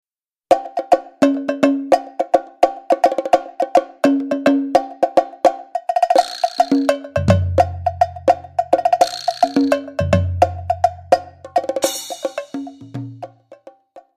Multi-Percussion